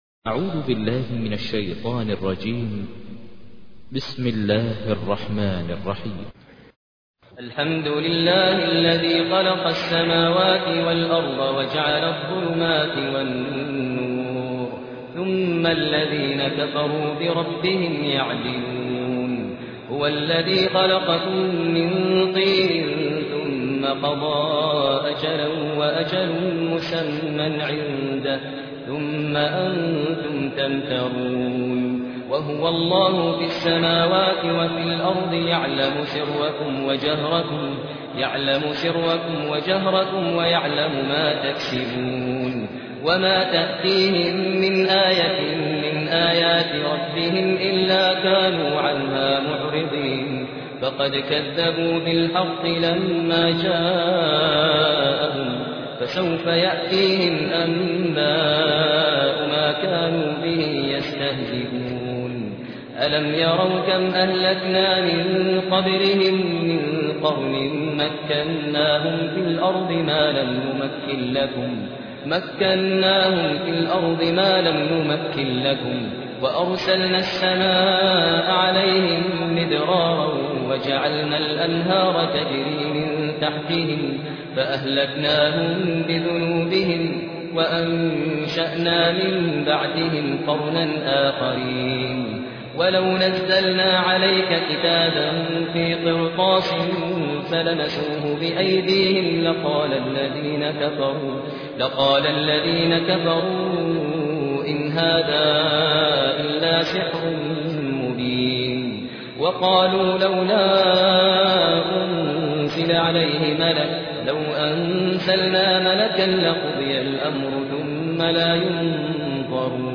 تحميل : 6. سورة الأنعام / القارئ ماهر المعيقلي / القرآن الكريم / موقع يا حسين